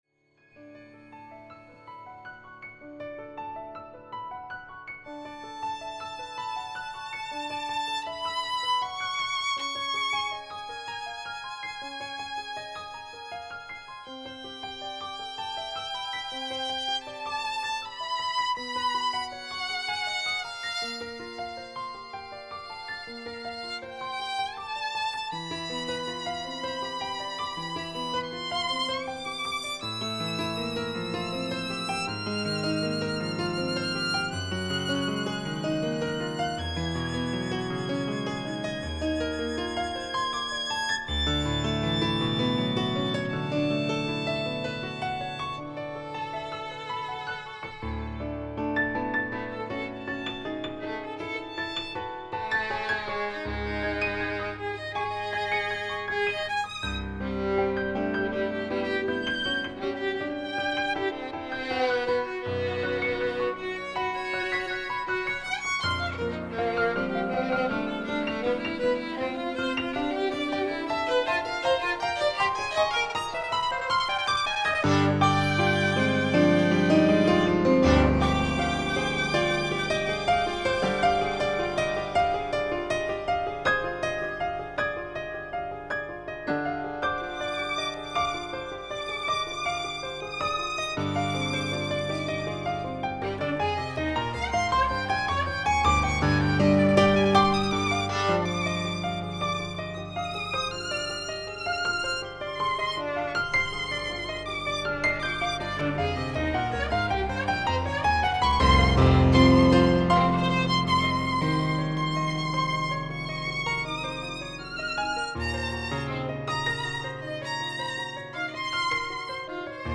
violin & piano
Highly lyrical, yet driven by rhythmic energy